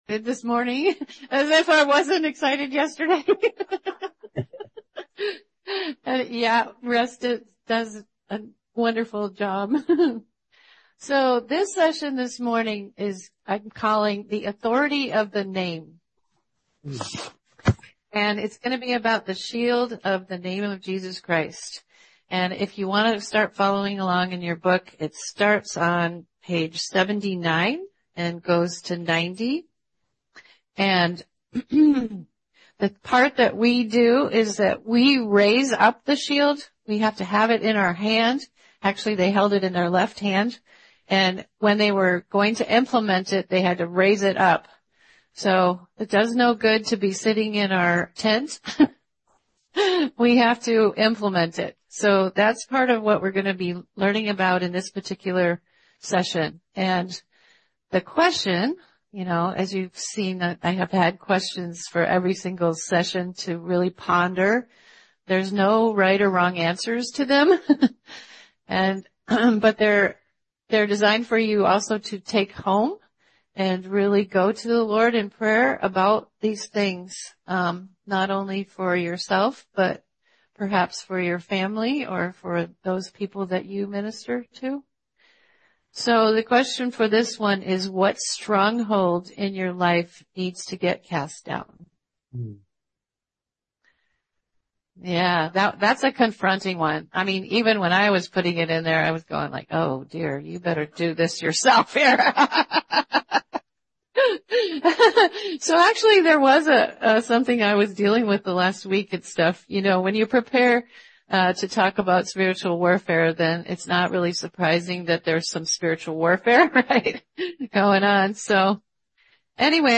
Armor of God Seminar 2024 Part 5 cat-aog